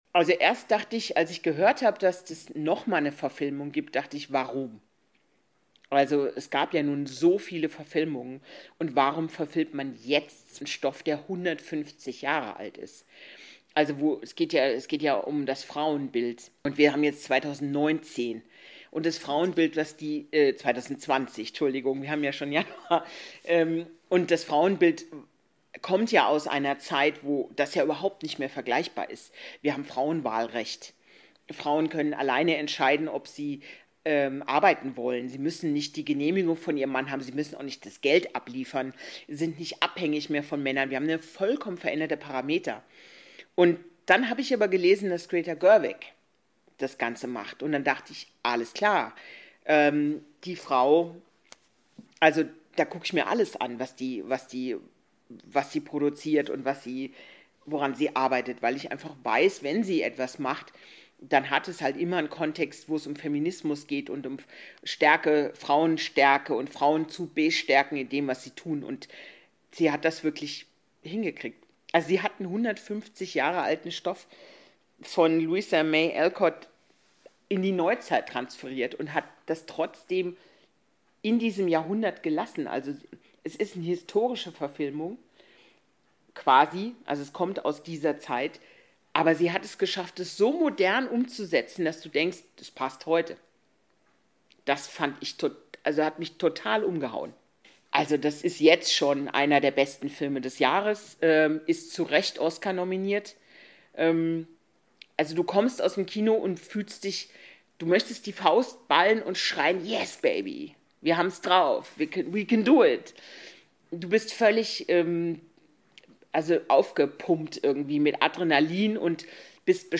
Meine Einschätzung des Filmes LITTLE WOMEN könnt Ihr Euch als Microfazit anhören: